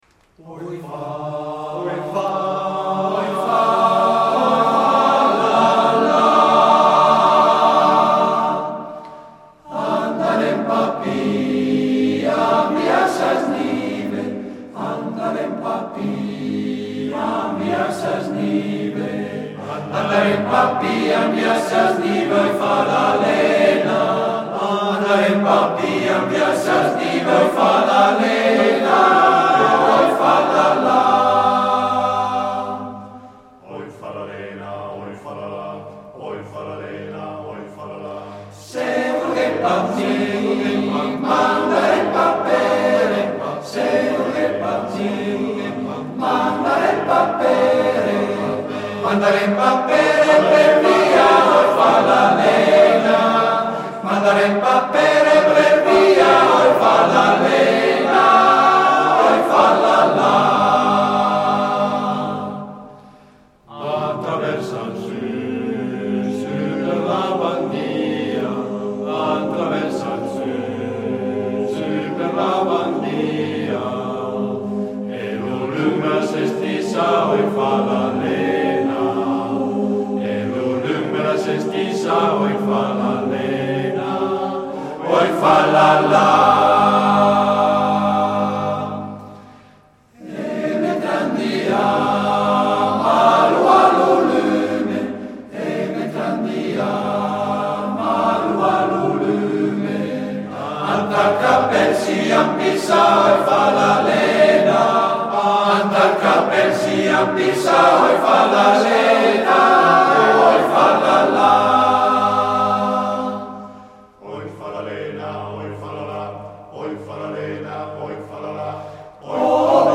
Esecutore: Gruppo Corale "La Baita"